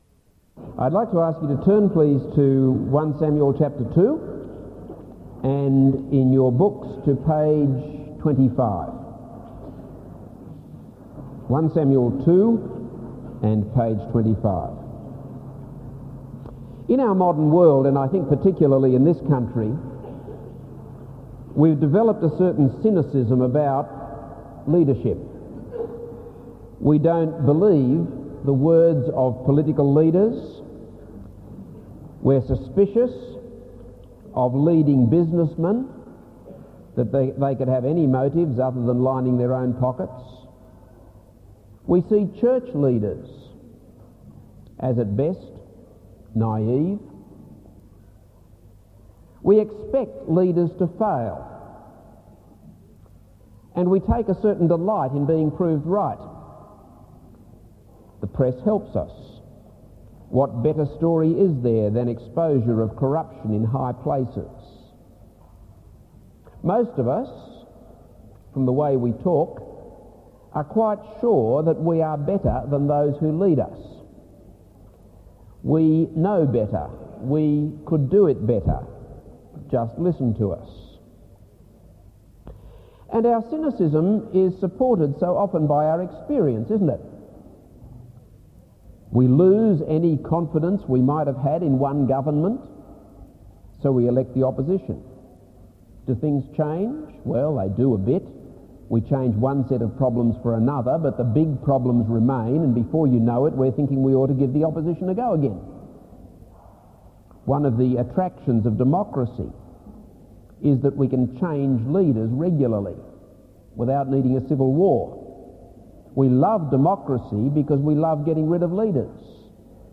This is a sermon on 1 Samuel 2-7.